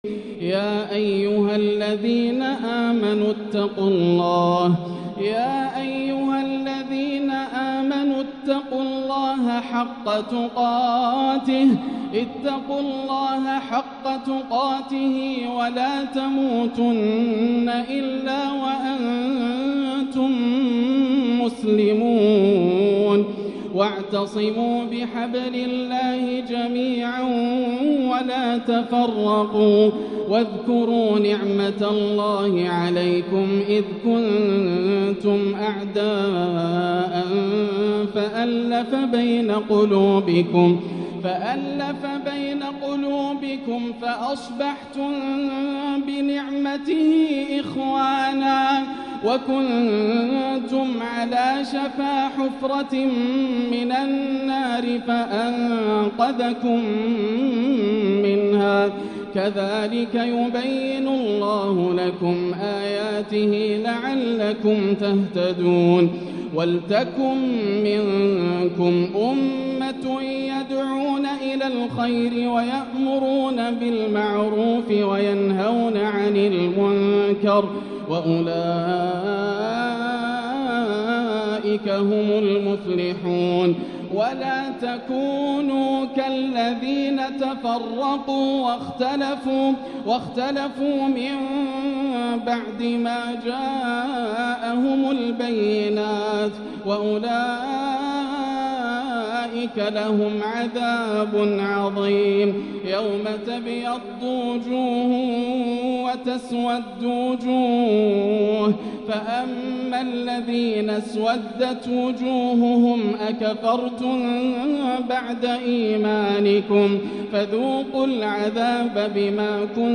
تلاوة تحبيرية بإنتقالات تَرنمية تُشنّف الأسماع > الروائع > رمضان 1445هـ > التراويح - تلاوات ياسر الدوسري